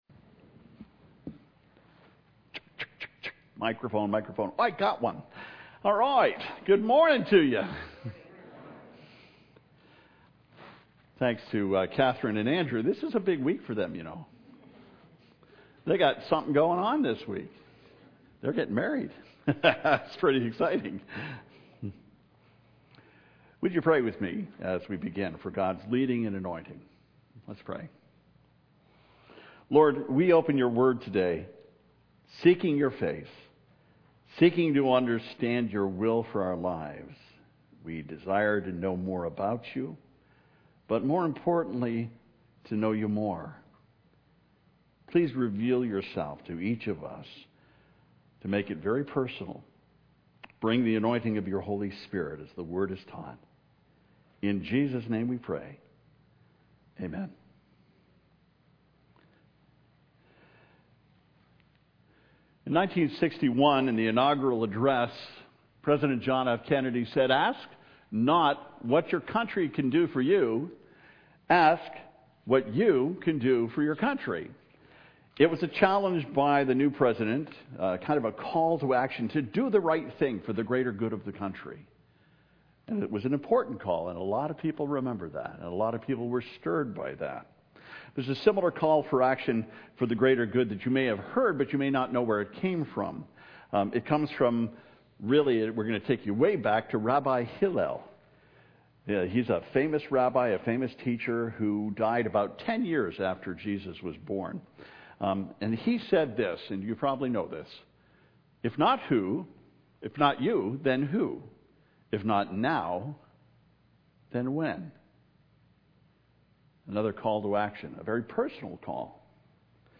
03-Sermon-Kings-3.mp3